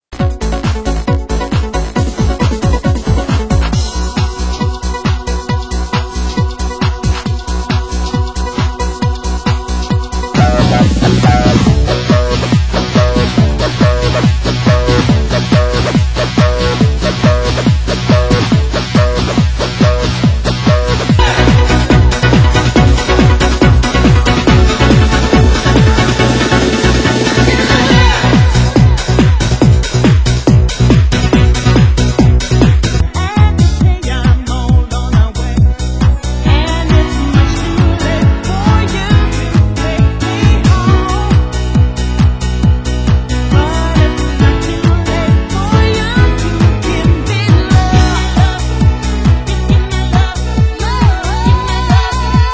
Genre: Trance